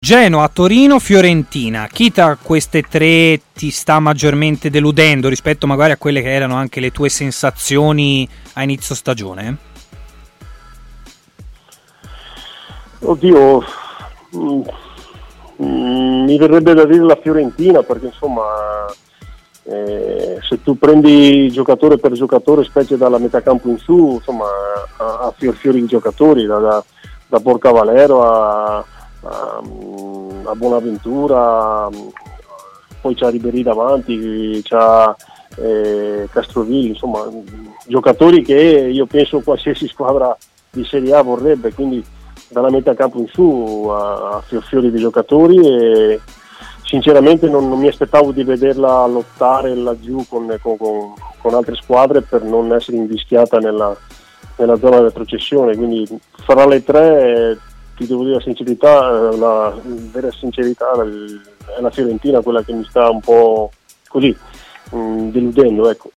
L'ex attaccante Filippo Maniero è intervenuto in diretta ai microfoni di TMW Radio, nel corso della trasmissione Stadio Aperto. Durante l'intervento ha parlato anche delle delusioni di questo campionato.